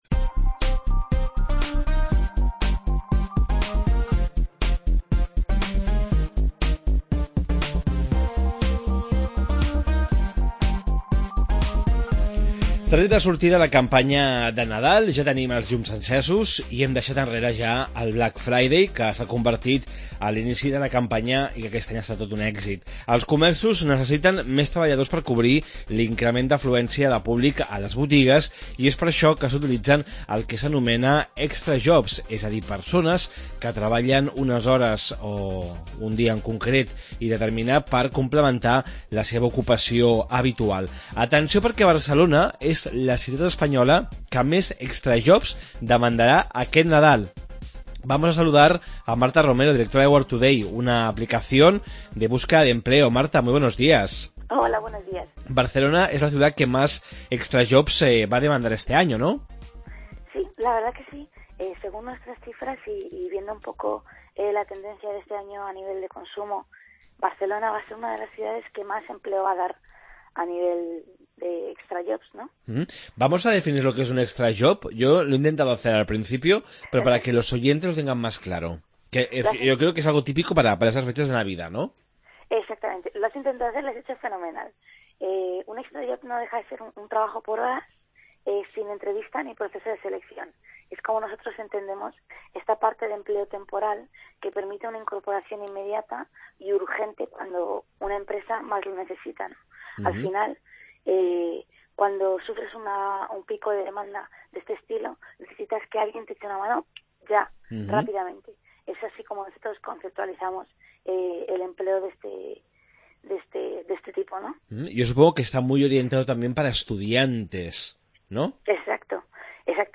Barcelona és la ciutat espanyola amb més ofertes laborals per aquesta campanya de Nadal. Què són els extrajobs? Entrevista